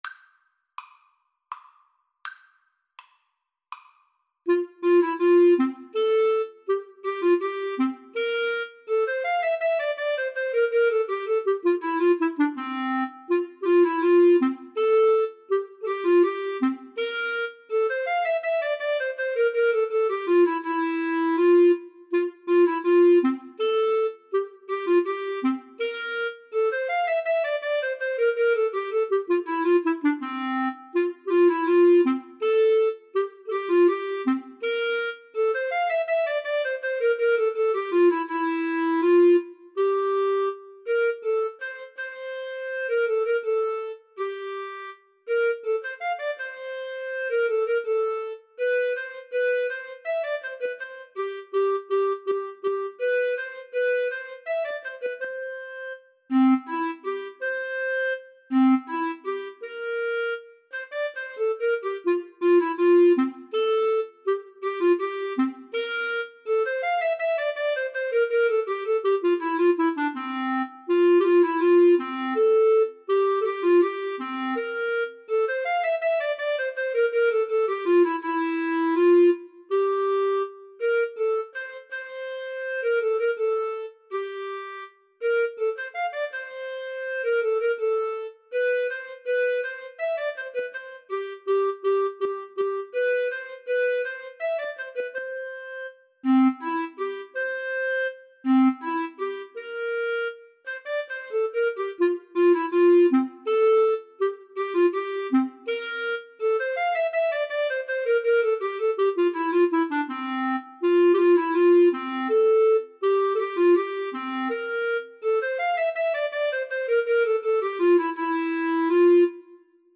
• Unlimited playalong tracks